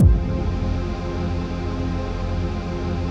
HOUSPAD05.wav